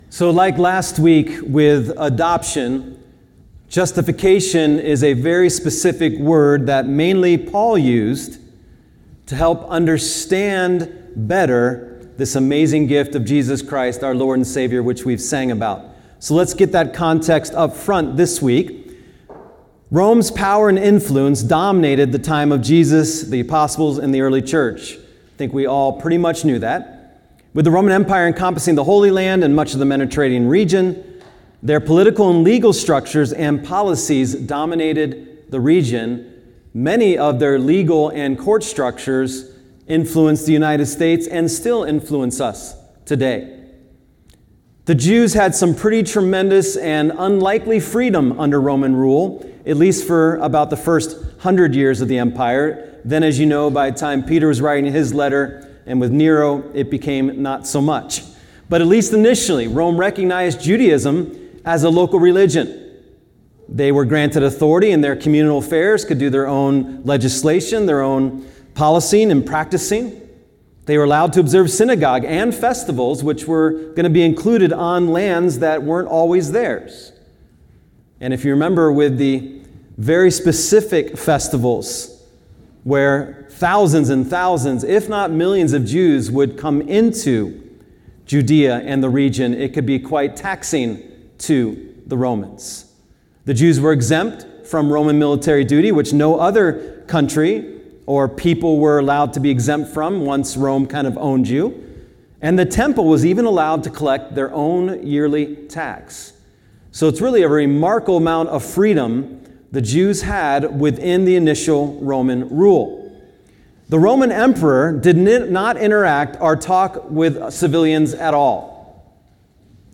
Sermons | Solid Rock Church